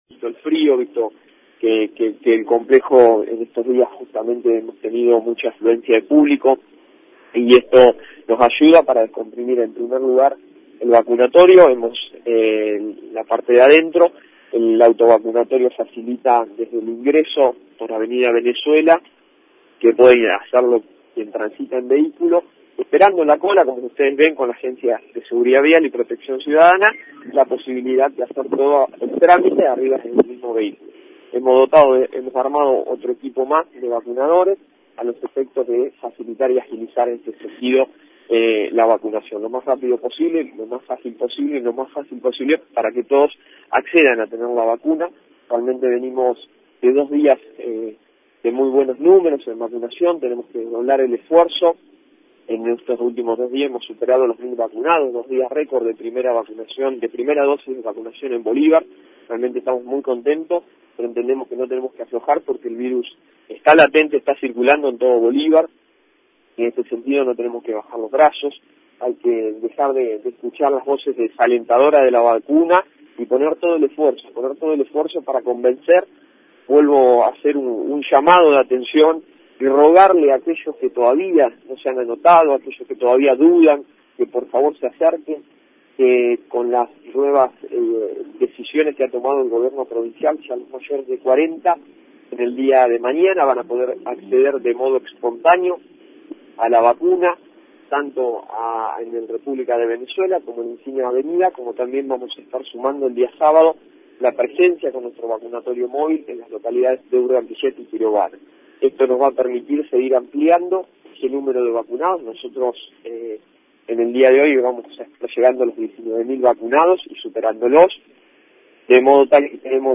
Conferencia de PrensaIntendente Marcos Pisano y Secretaria e Salud María E. Jofré